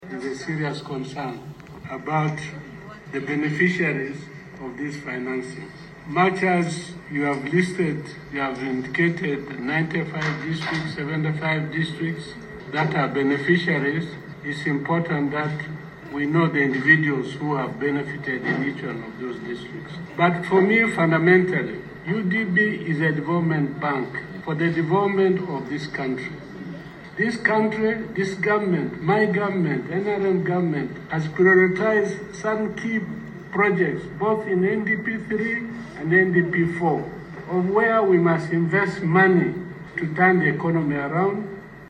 Members of Parliament on the Committee of National Economy concerned about distribution of the Uganda Development Bank (UDB) financing as the government seeks a US$275 million loan to recapitalise the bank.
Hon. James Baba (NRM, Koboko County) stressed the need for transparency regarding individual beneficiaries and alignment with national economic priorities.